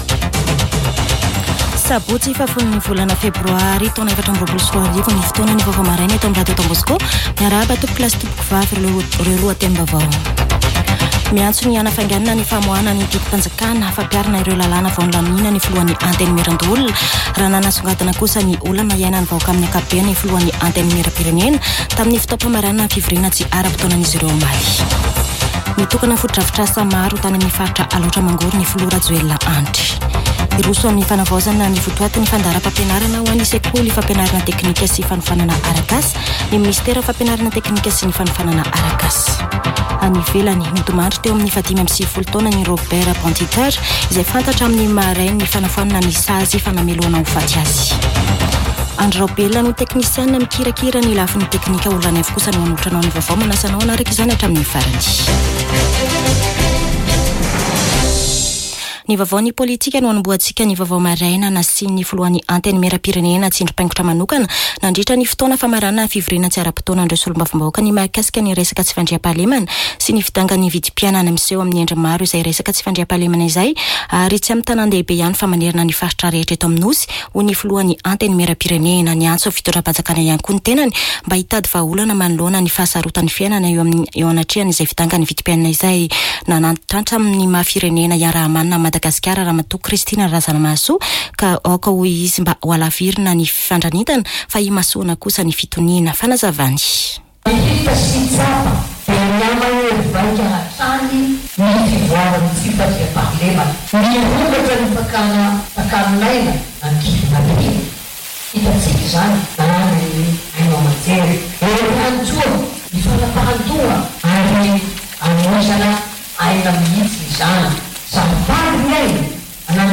[Vaovao maraina] Sabotsy 10 febroary 2024